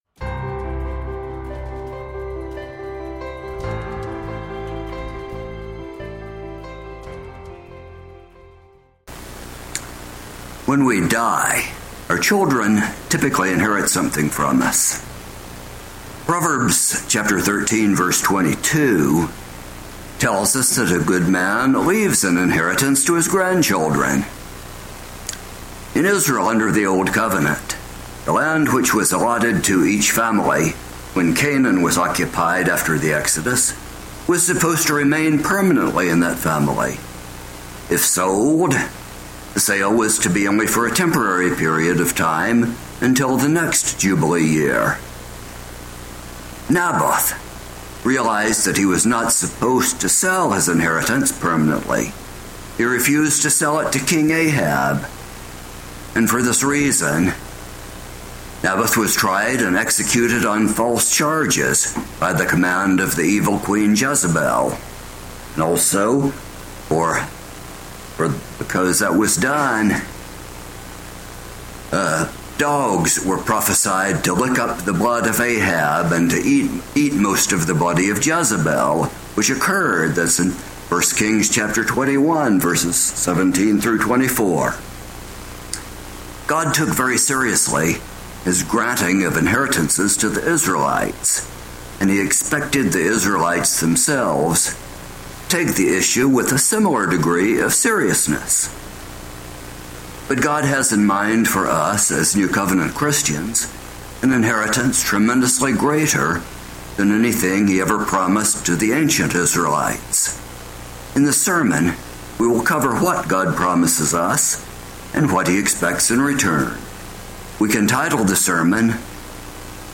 Given in Roanoke and Kingsport on Sabbath, December 9, 2023